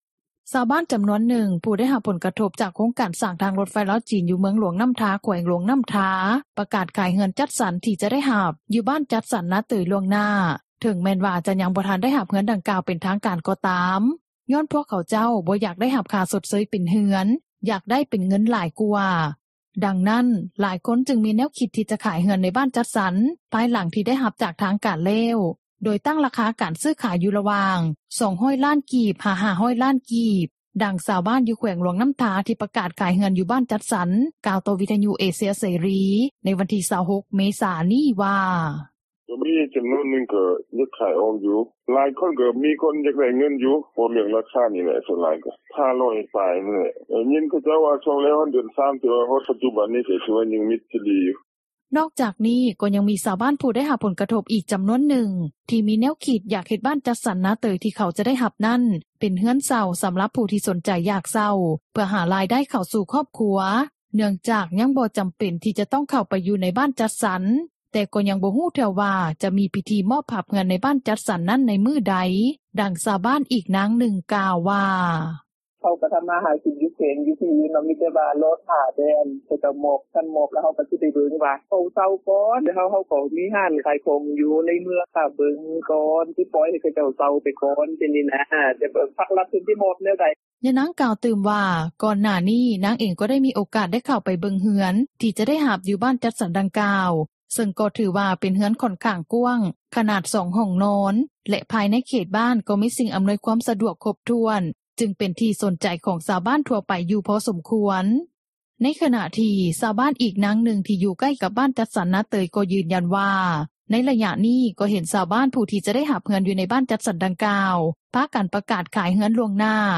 ດັ່ງຊາວບ້ານ ຢູ່ແຂວງຫຼວງນໍ້າທາ ທີ່ປະກາດຂາຍເຮືອນ ຢູ່ບ້ານຈັດສັນ ກ່າວຕໍ່ວິທຍຸເອເຊັຽເສຣີ ໃນວັນທີ່ 26 ເມສາ ນີ້ວ່າ: